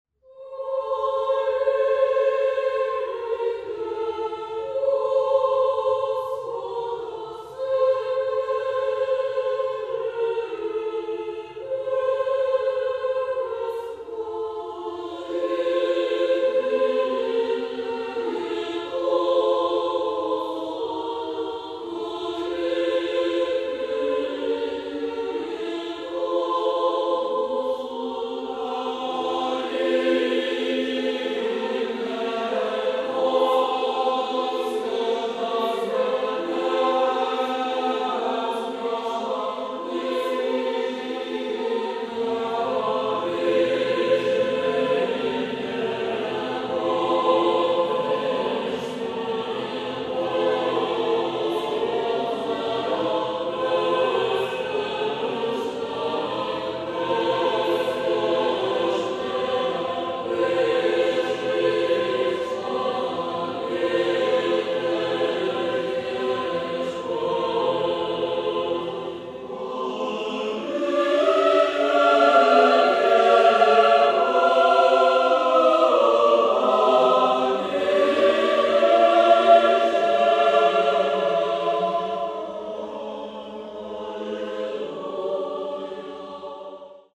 Церковная